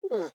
Minecraft Version Minecraft Version snapshot Latest Release | Latest Snapshot snapshot / assets / minecraft / sounds / mob / armadillo / ambient2.ogg Compare With Compare With Latest Release | Latest Snapshot